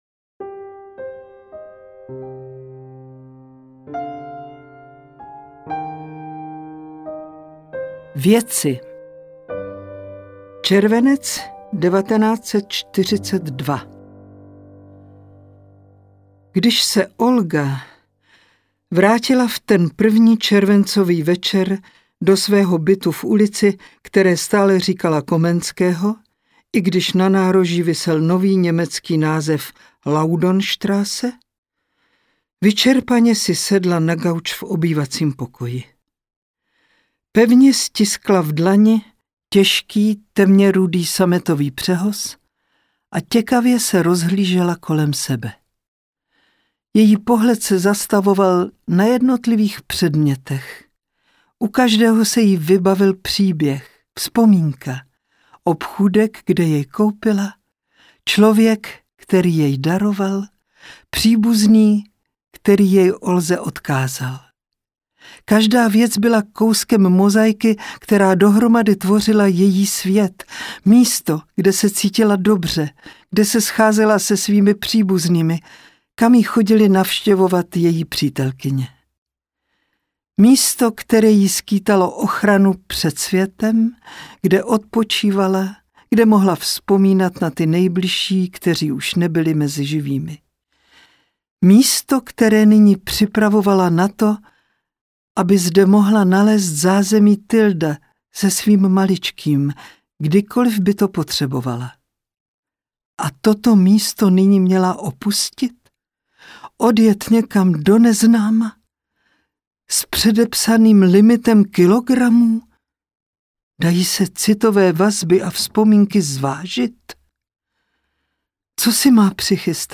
Ukázka z knihy
tylda-audiokniha